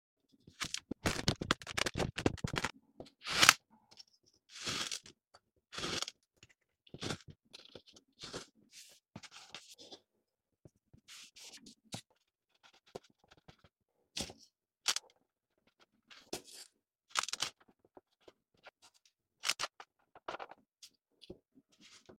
kinetic sand shape ASMR satisfaisant sound effects free download